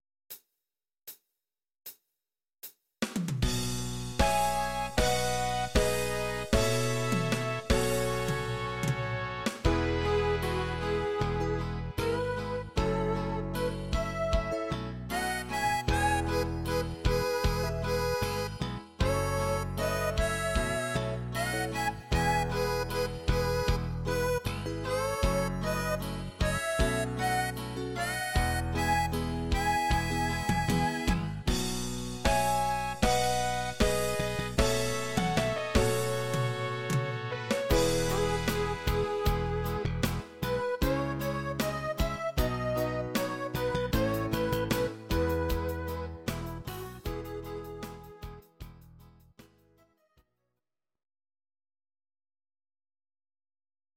Audio Recordings based on Midi-files
Pop, Duets, 1980s